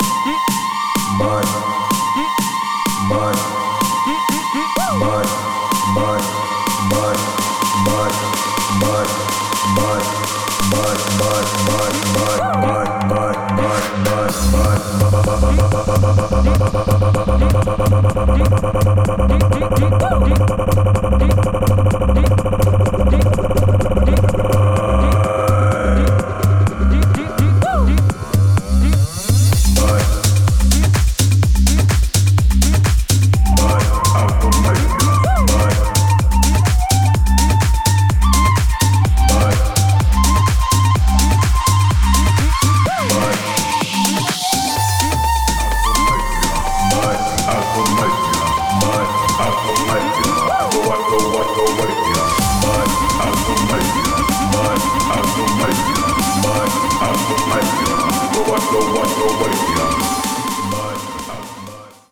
vibrant tech house tracks